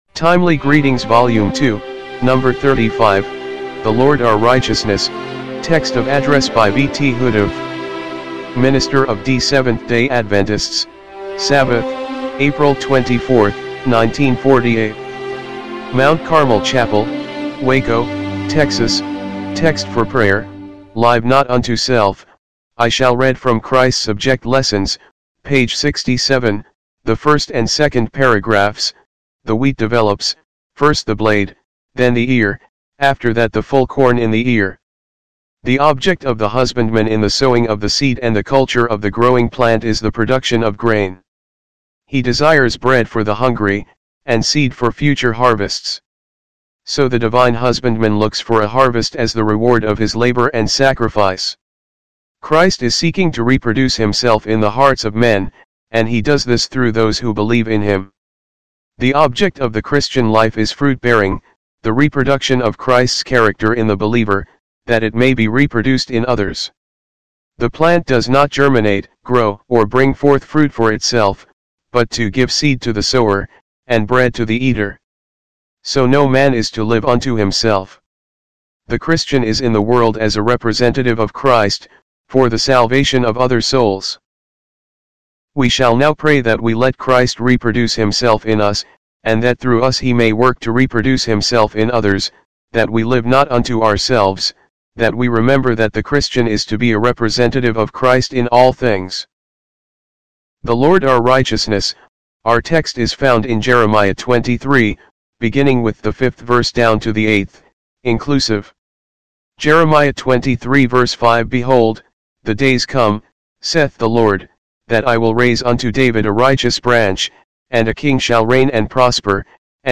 timely-greetings-volume-2-no.-35-mono-mp3.mp3